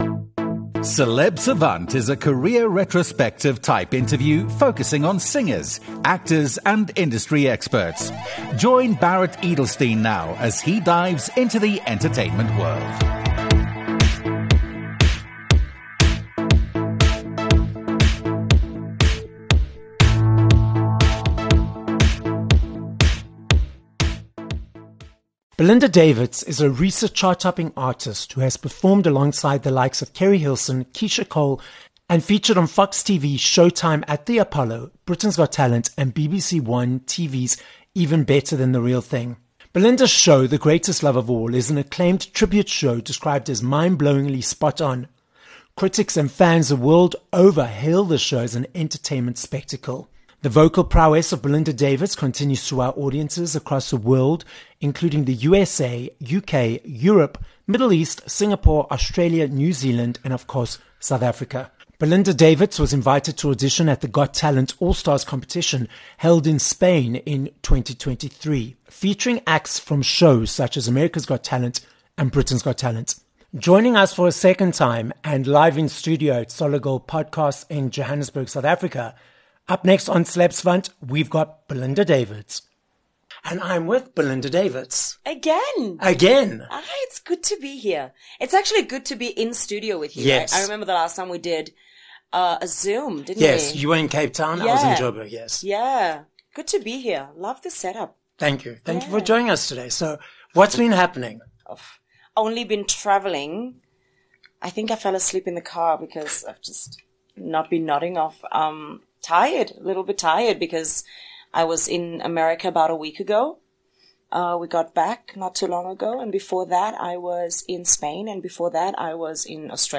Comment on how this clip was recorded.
This episode was recorded live at Solid Gold Podcasts, Randburg, Johannesburg, South Africa Website